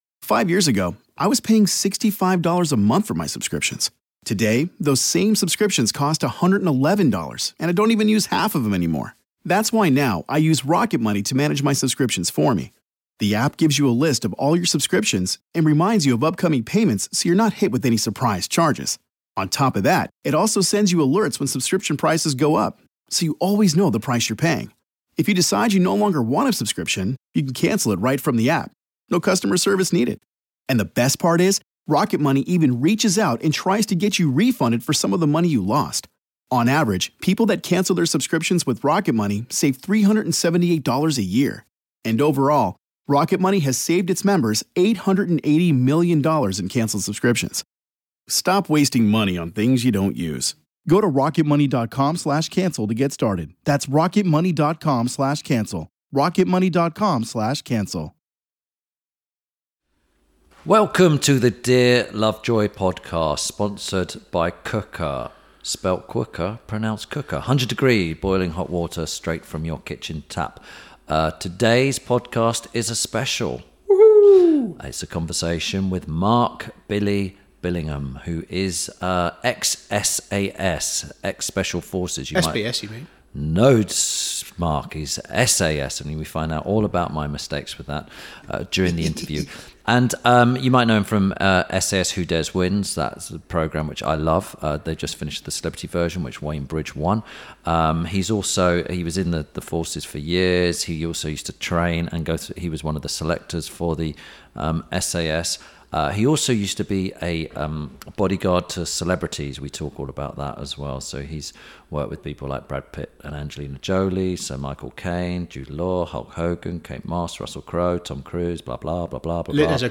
Ep. 125 – MARK ‘BILLY’ BILLINGHAM - A Conversation With… – INTERVIEW SPECIAL
This week Tim Lovejoy talks to former SAS member, bodyguard turned TV personality Mark ‘Billy’ Billingham. Tim and Billy discuss the gruelling SAS selection process, the ups and downs of a career in the special forces and what it’s like to be a bodyguard to the likes of Brad P...